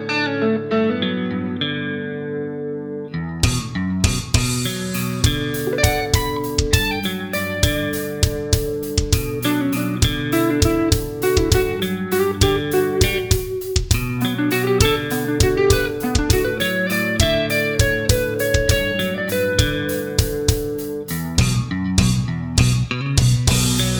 Minus Main Guitar Rock 7:24 Buy £1.50